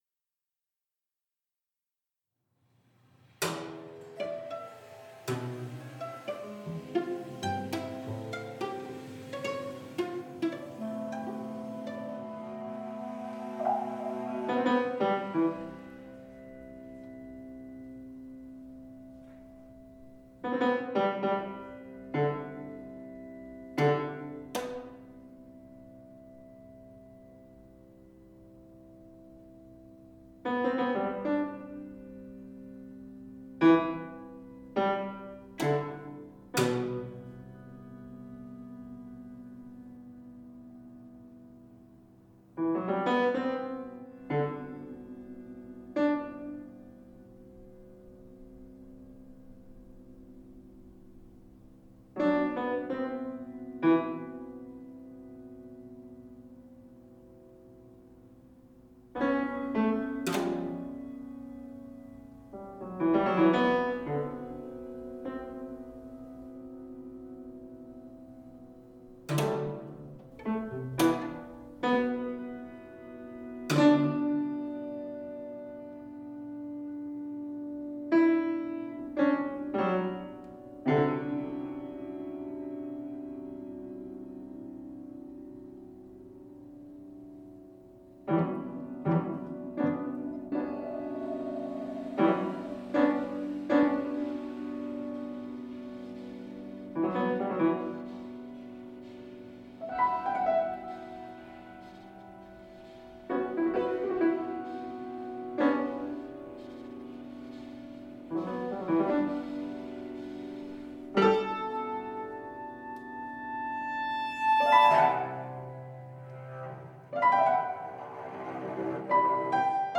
Decay periods and sympathetic resonances are explored on both minute and structural levels reflecting remnants and fragments that may linger for years after an incident has occurred.